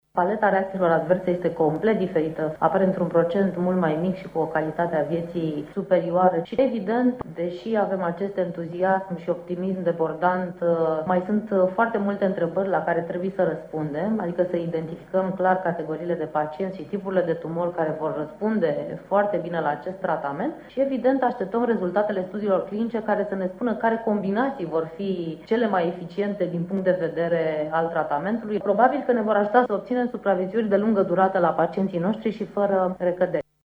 medicul oncolog